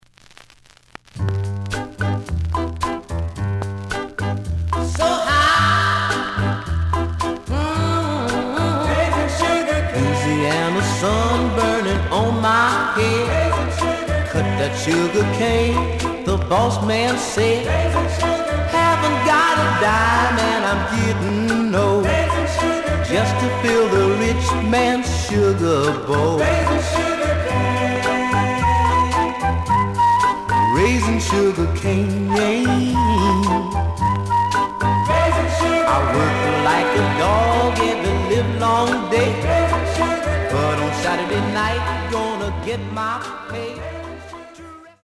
The listen sample is recorded from the actual item.
●Genre: Soul, 60's Soul
Slight edge warp.